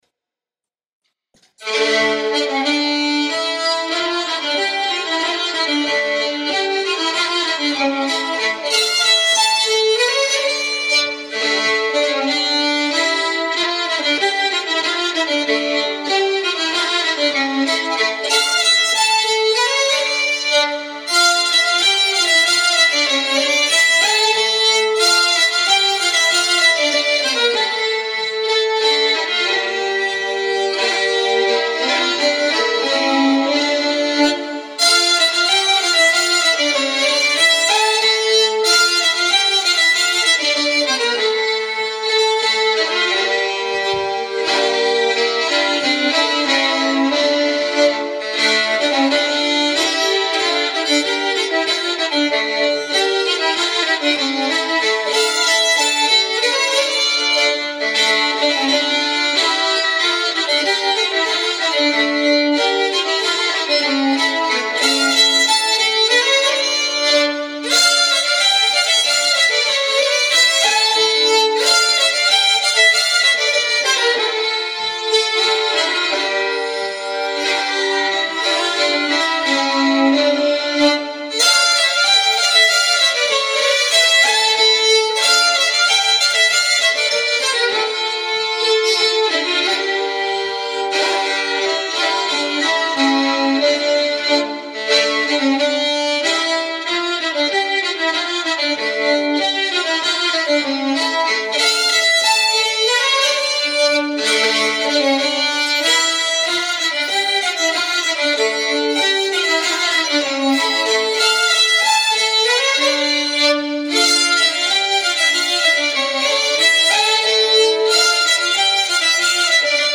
Riksspelman på Svensk Säckpipa
Låt nr. 11 D-moll.